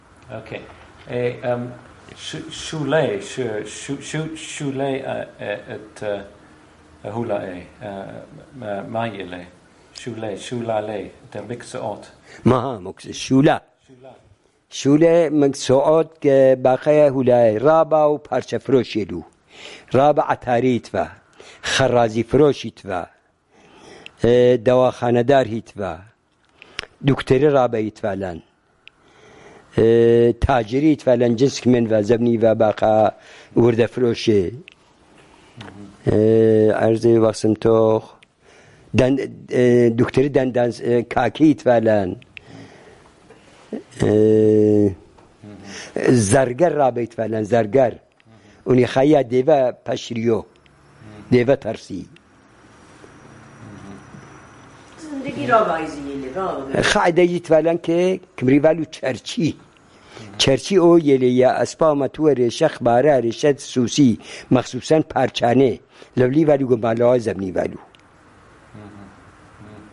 Sanandaj, Jewish: Professions of the Jews